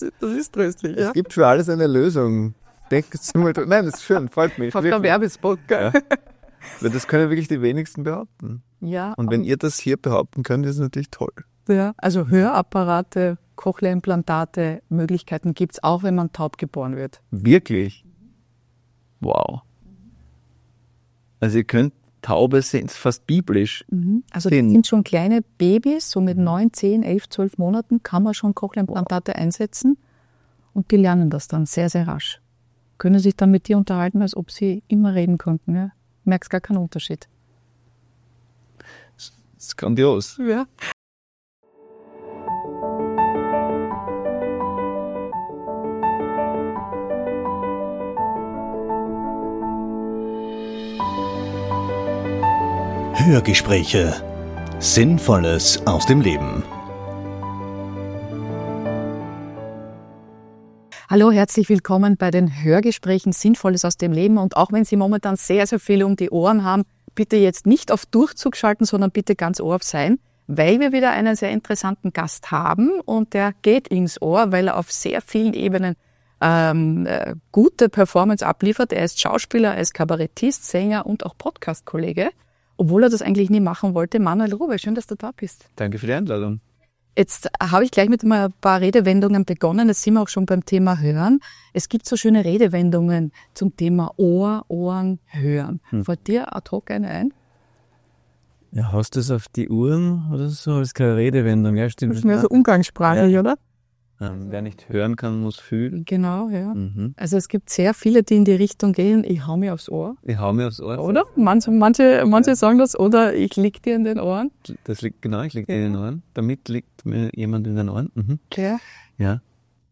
Wie schön, dass er bei uns nun auf dem gegenüberliegenden Platz genommen hat – als Gast im neuen Hörgespräch.
Von den Sonnen- und Schattenseiten seines Berufs und davon, warum Musik für ihn der Emotionsverstärker schlechthin ist. Ein wirklich spannendes Gespräch mit einem ehrlichen und empathischen Manuel Rubey – für Fans ein echtes Zuckerl!